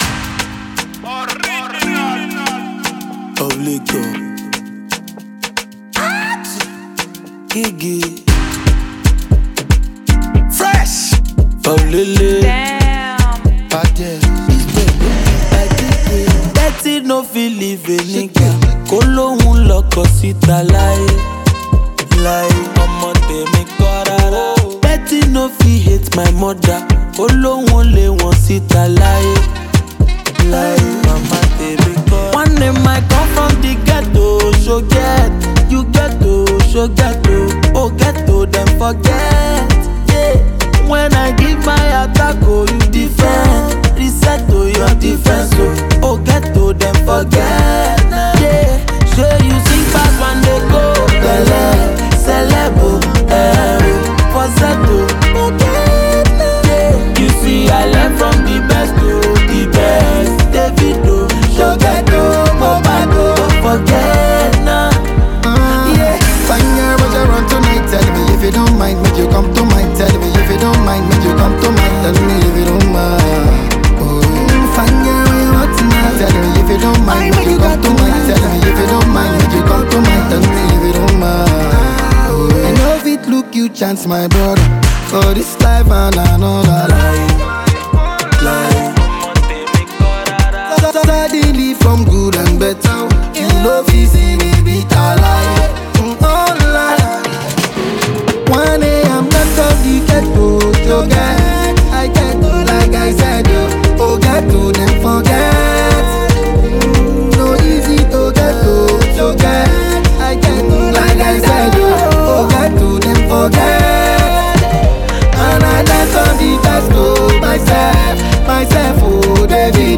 The album mixes Afrobeats with pop and hip-hop sounds.
fun, lively, and full of good vibes